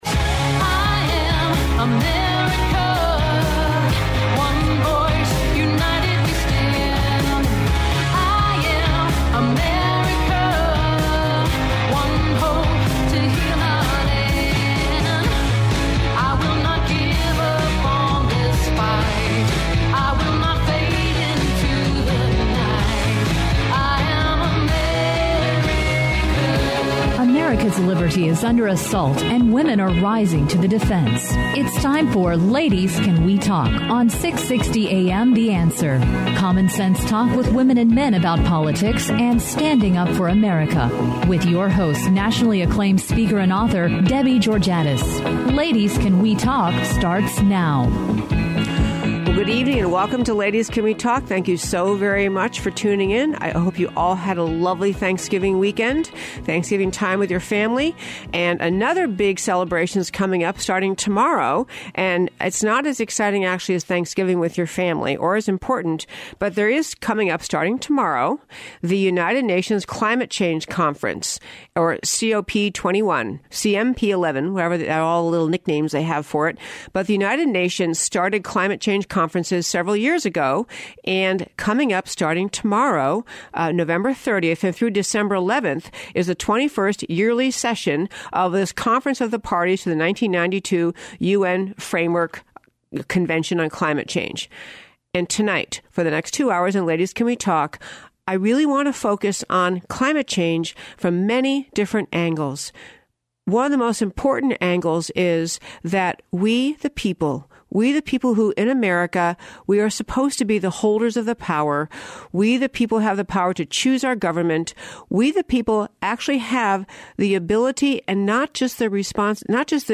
Climate Change: UN-IPCC – Credibility?; Interview with Congressman Lamar Smith
Congressman Lamar Smith, Chair of the Science Committee of the US House of Representatives, calls in to discuss the bizarre and unacceptable behavior of the NOAA in refusing to share its temperature measurement data.